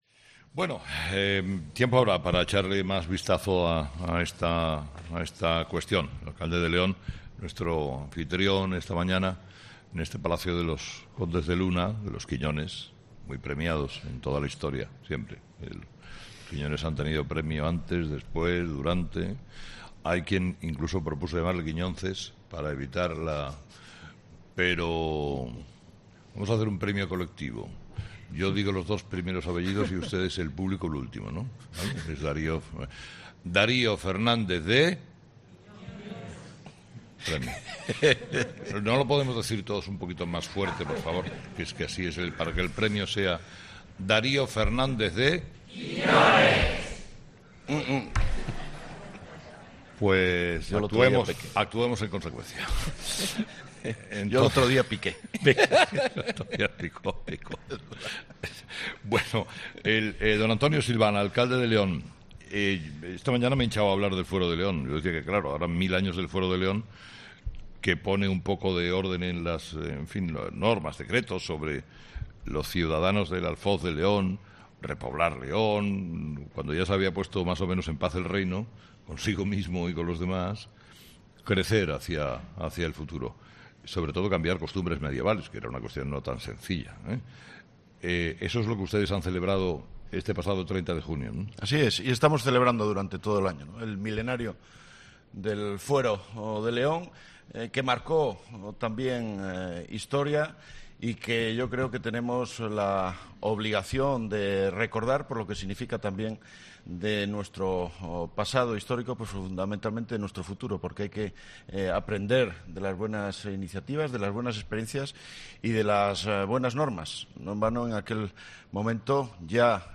Carlos Herrera viaja a León para celebrar los mil años del nacimiento de su fuero